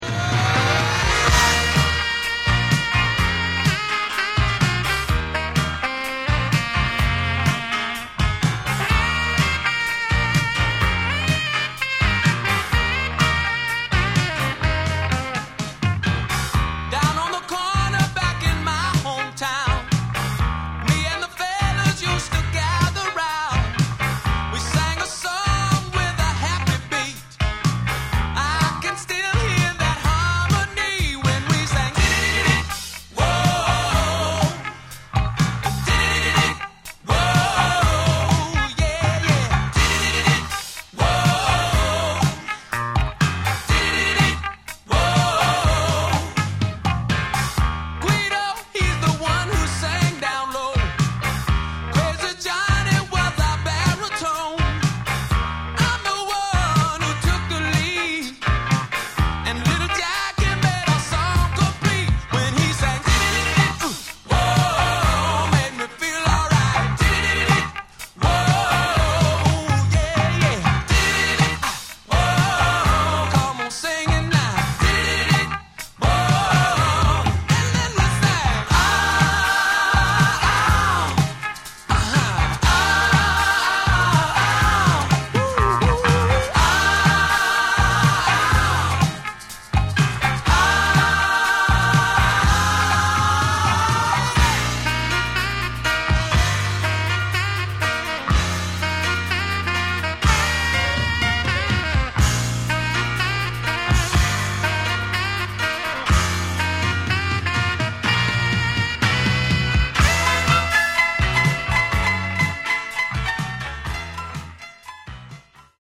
Genre: Southern Rock